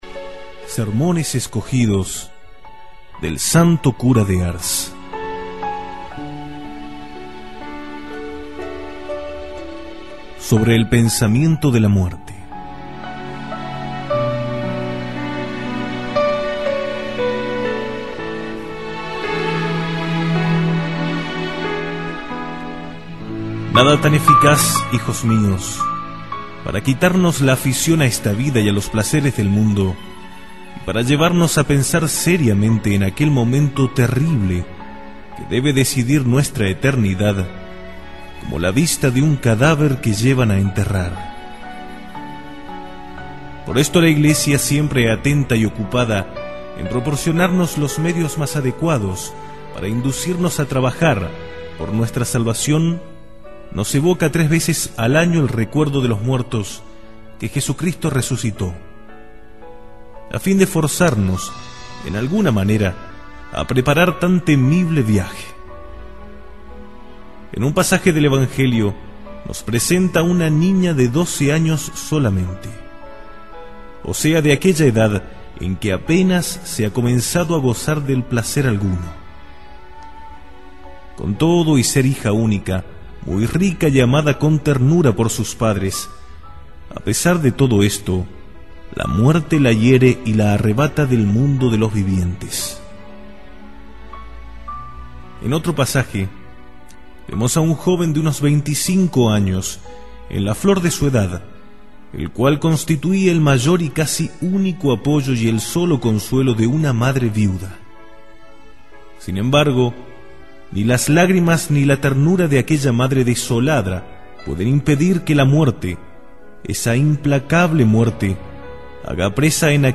Audio–libro
Sermon-del-Santo-Cura-de-Ars-El-pensamiento-de-la-muerte.mp3